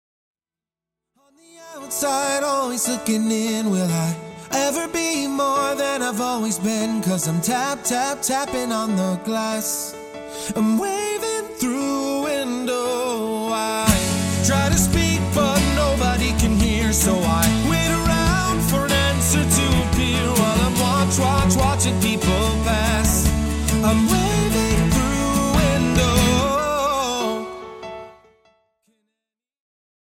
Chant et Piano